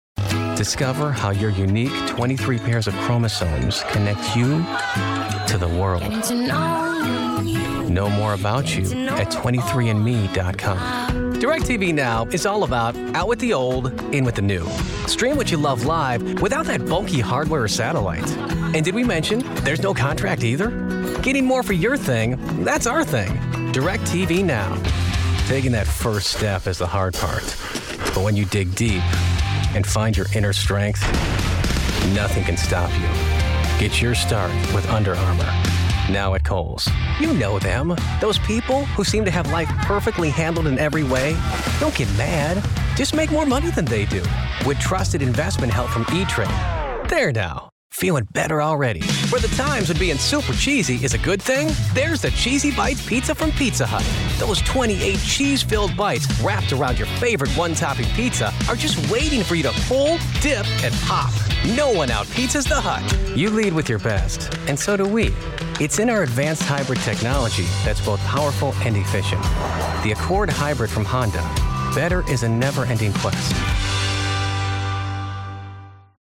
Guy next door, Engaging, Friendly, Conversational, Real, Believable, Upbeat, Energetic, Warm, Witty, Sincere, Sarcastic, Confident